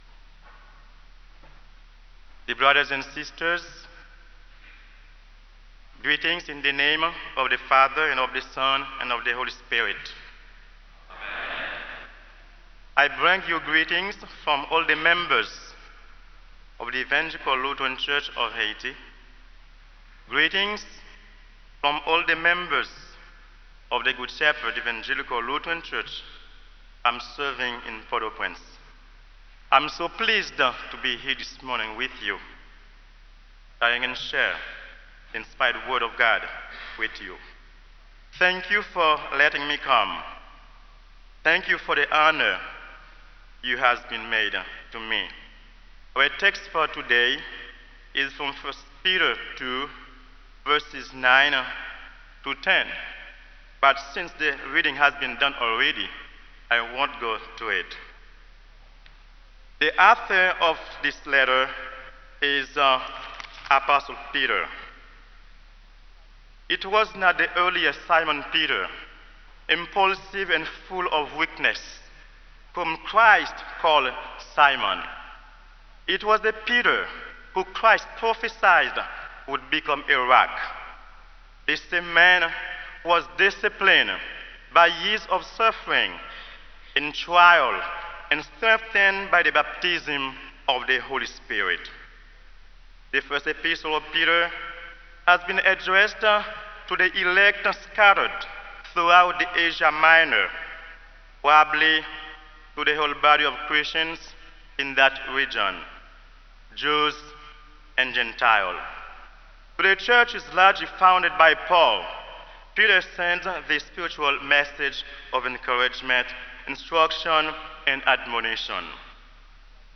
Kramer Chapel Sermon - October 30, 2003